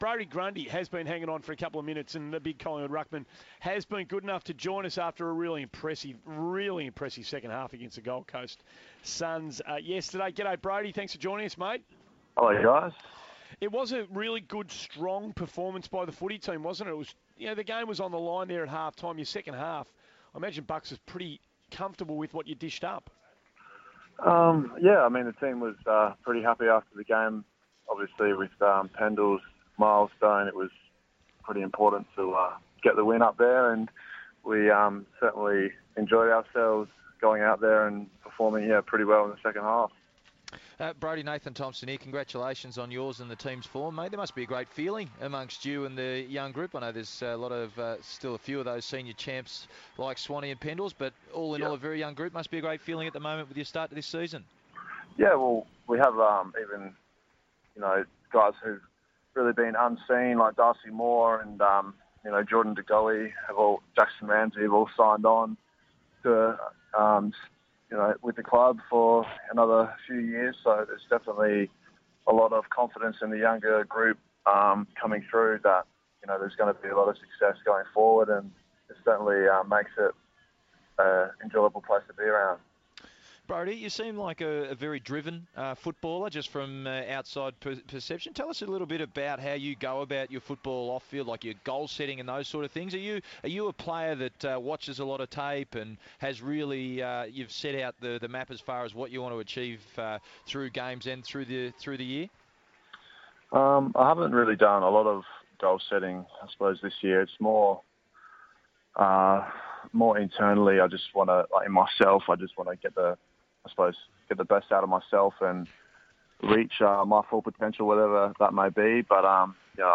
Collingwood ruckman Brodie Grundy speaks with Andy Maher, Scott Lucas and Nathan Thompson following the Pies win over Gold Coast.